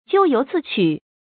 jiù yóu zì qǔ
咎由自取发音
成语注音 ㄐㄧㄨˋ ㄧㄡˊ ㄗㄧˋ ㄑㄩˇ
成语正音 咎，不能读作“jiū”。